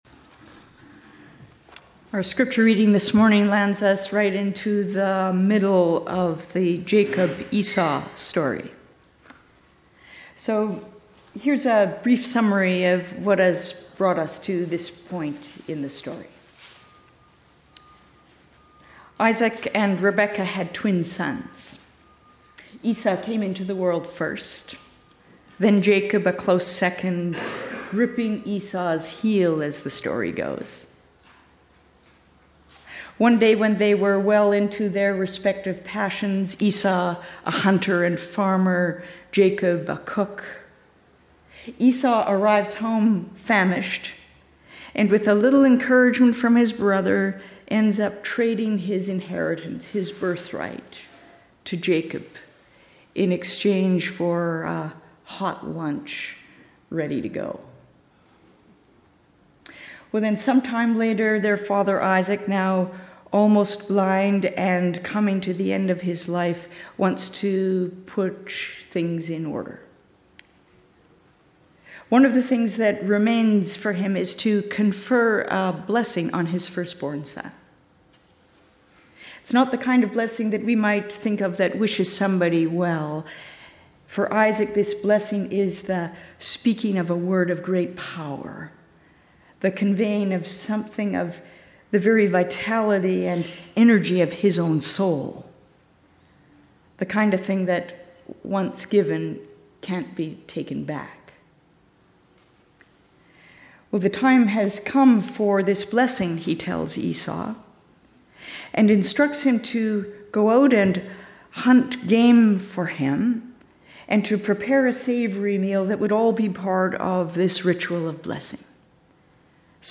Sermons | James Bay United Church